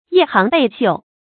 夜行被繡 注音： ㄧㄜˋ ㄒㄧㄥˊ ㄆㄧ ㄒㄧㄨˋ 讀音讀法： 意思解釋： 謂身穿錦繡衣服在黑夜行走。